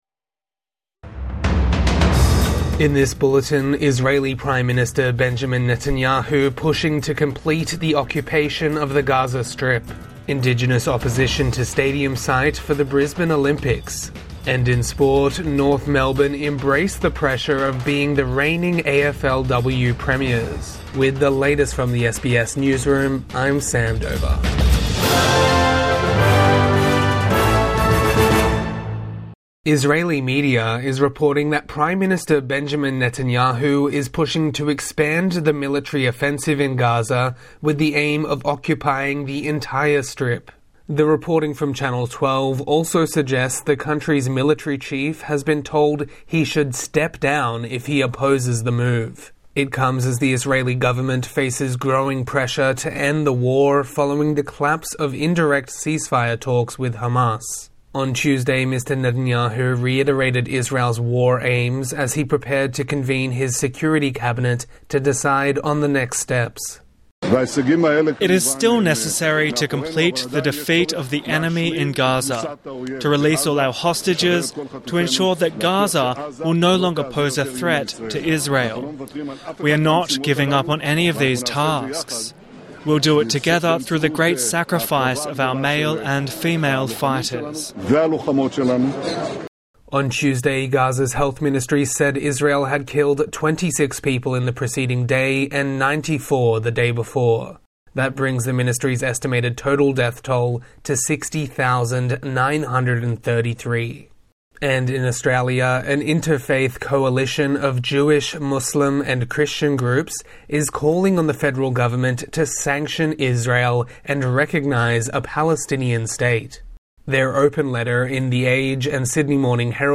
Netanyahu pushes for full occupation of Gaza | Morning News Bulletin 6 August 2025